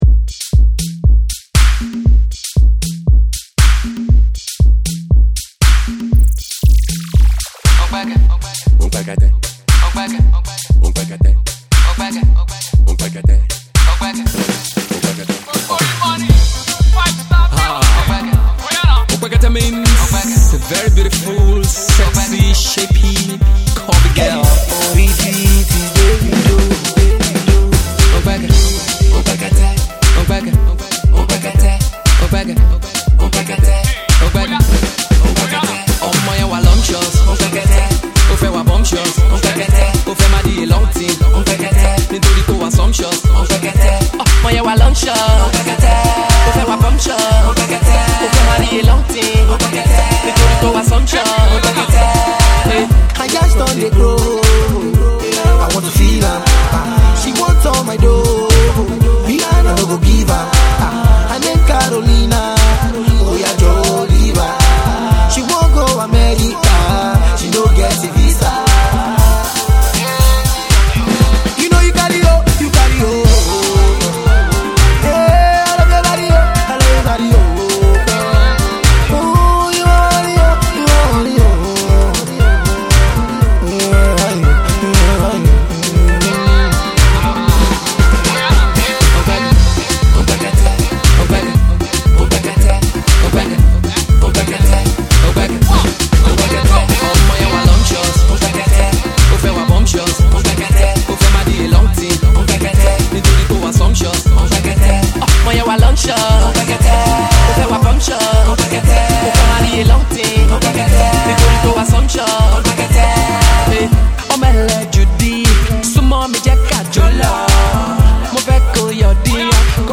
club-banging cut